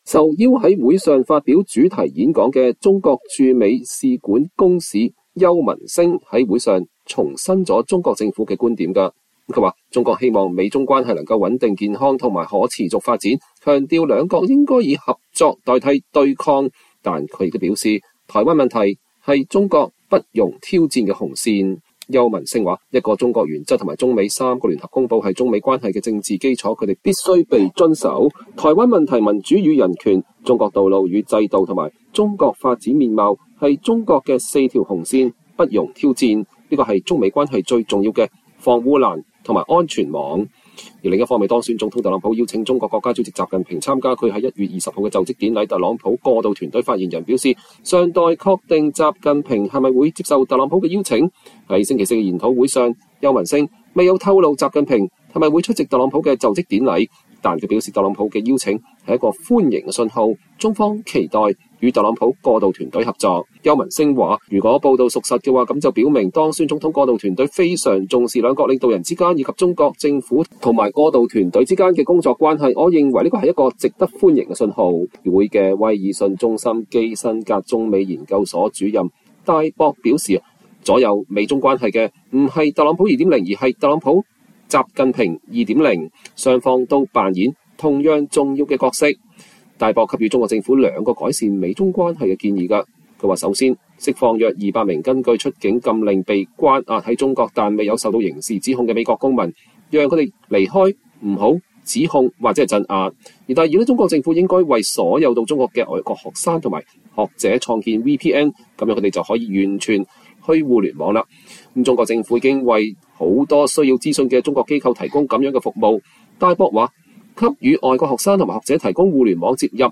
中國駐美使館公使邱文星(右)在中資背景智庫中美研究中心的研討會上發表主題演講和回答提問。